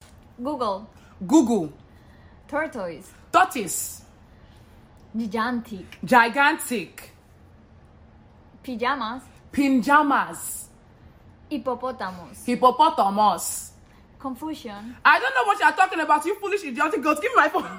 Colombian 🇨🇴 vs Nigerian🇳🇬 accent sound effects free download